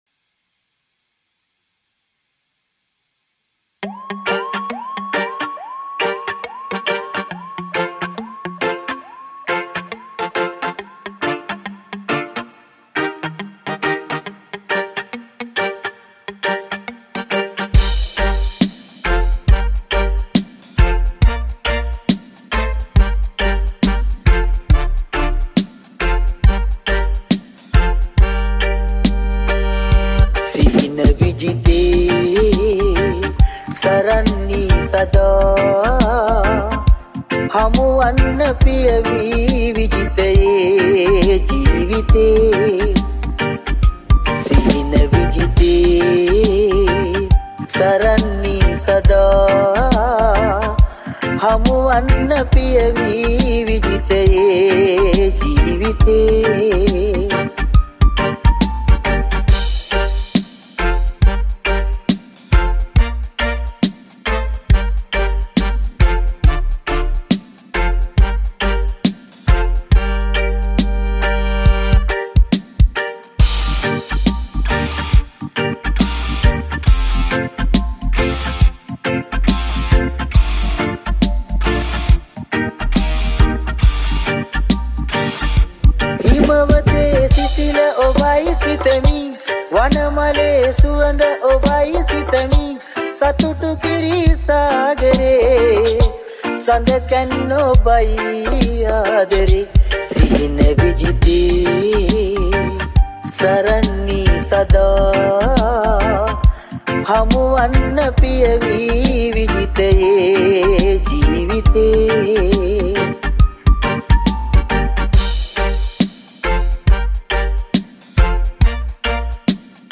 Reggae Version